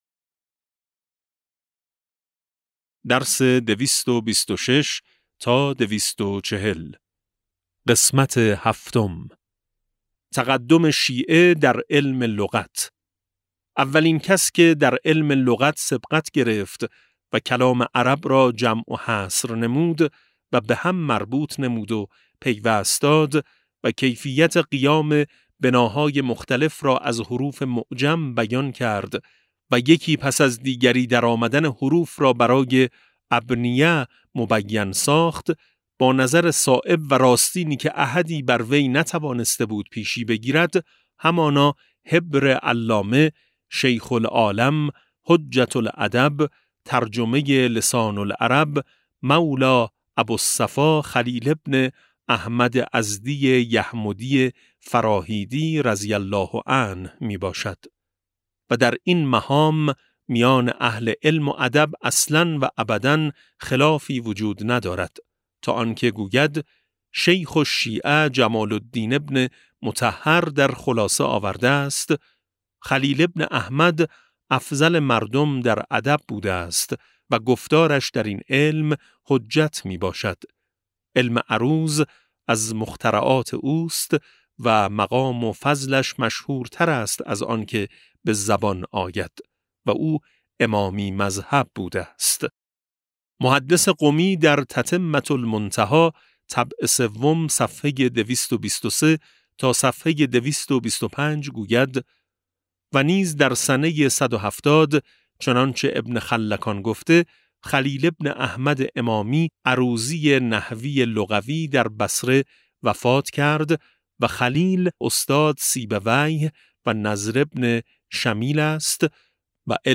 کتاب صوتی امام شناسی ج 16 و17 - جلسه7